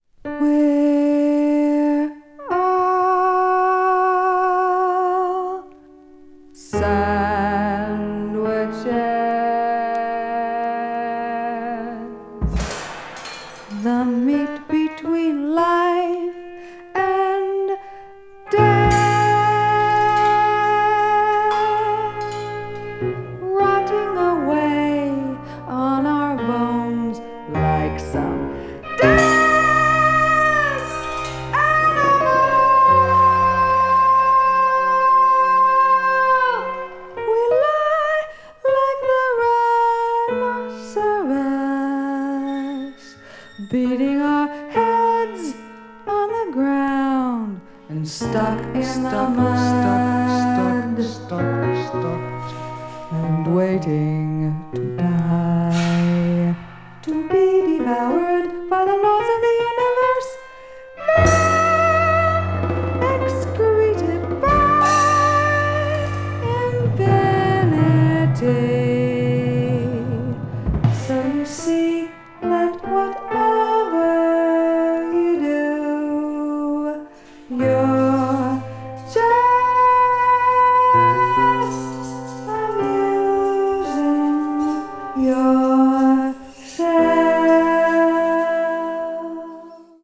avant-garde   avant-jazz   contemporary jazz   jazz vocal